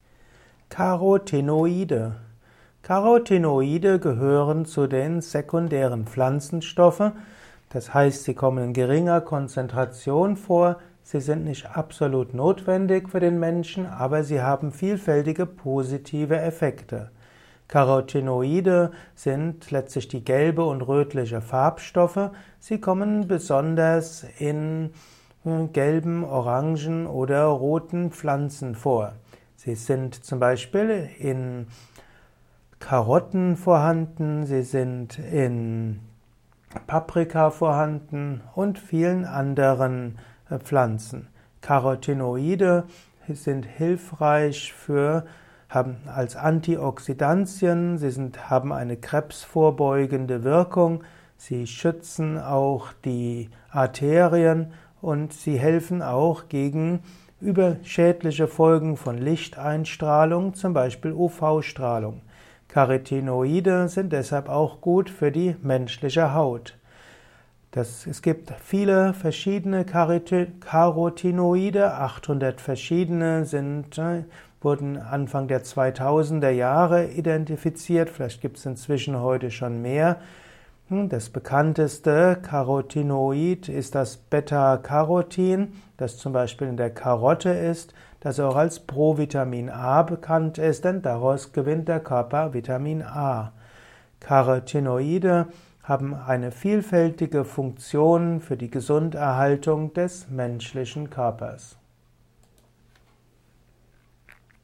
Erfahre Wissenswertes über Carotinoide in diesem Kurzvortrag